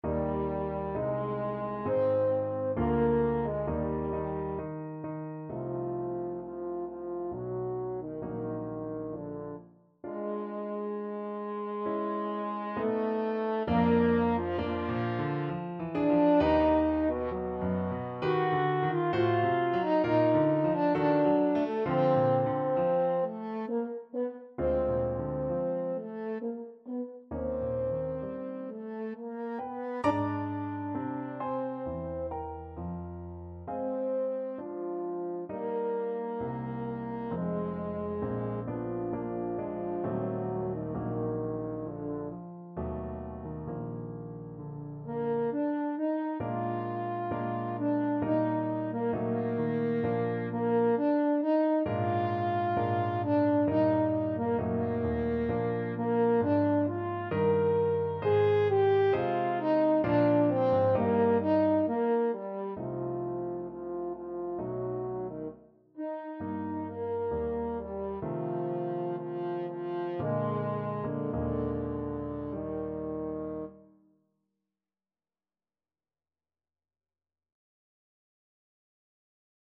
French Horn
3/4 (View more 3/4 Music)
=66 Andante sostenuto
Classical (View more Classical French Horn Music)
brahms_andante_sostenuto_HN.mp3